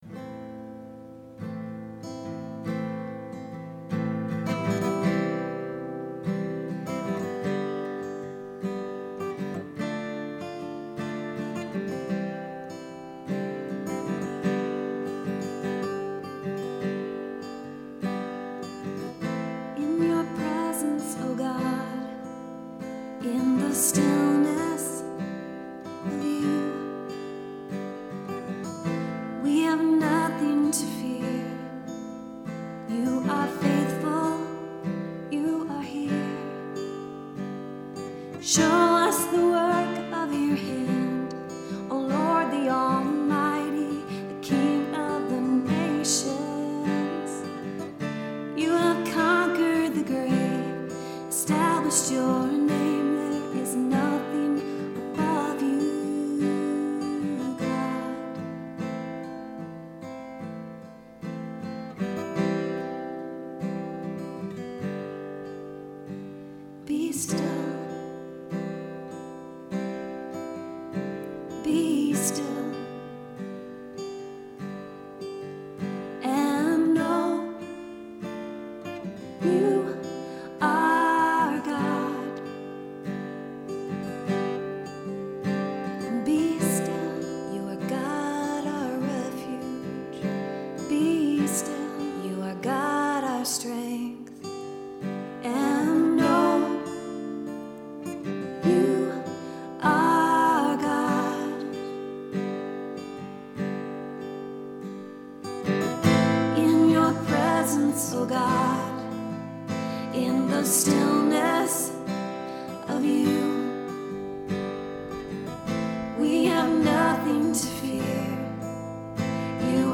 worship single